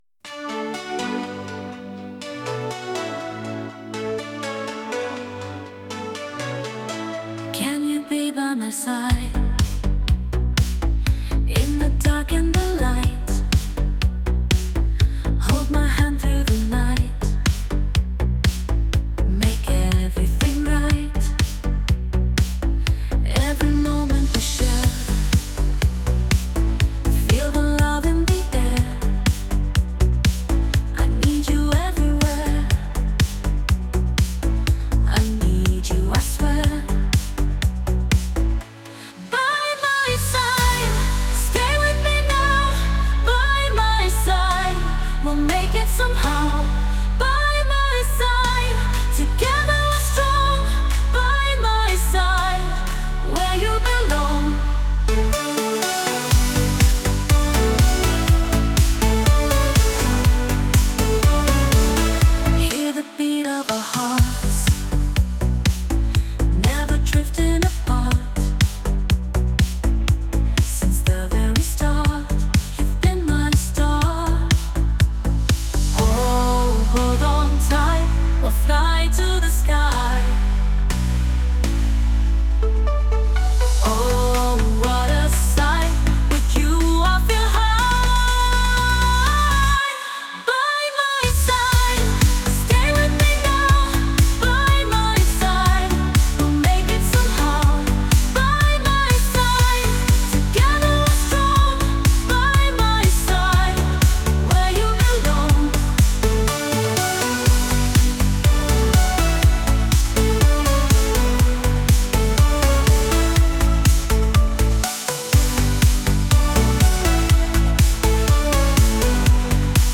Music EDM Music